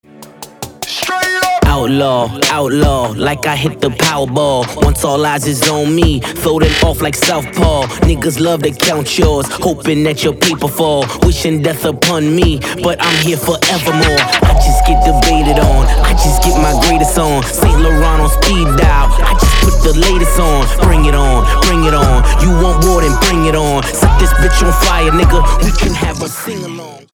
мужской вокал
Хип-хоп
Rap
Bass